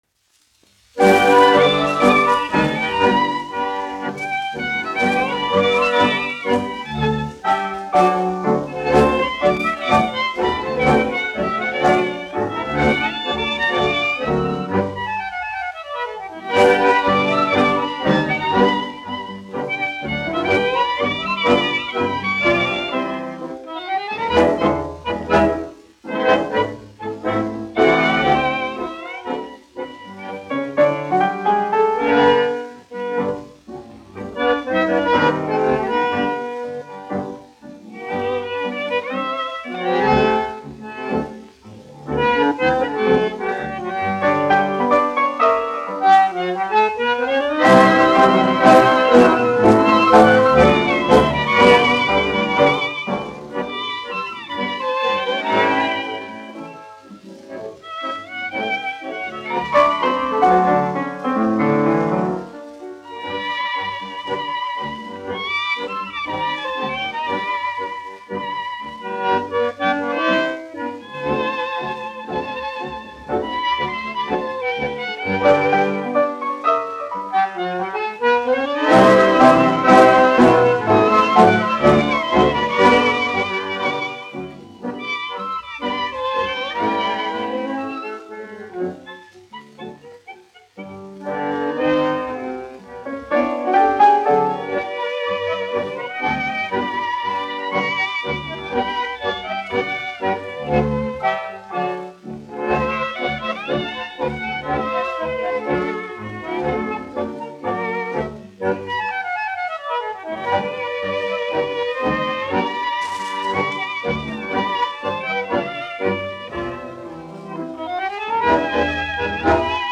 1 skpl. : analogs, 78 apgr/min, mono ; 25 cm
Deju orķestra mūzika
Populārā instrumentālā mūzika
Skaņuplate